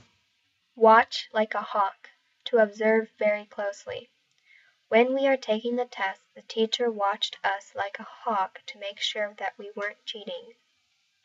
英語ネイティブによる発音はは下記のリンクをクリックしてください。